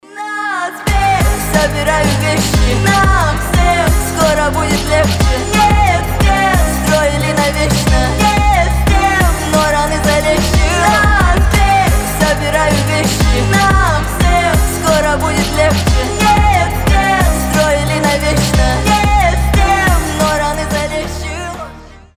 вдохновляющие
воодушевляющие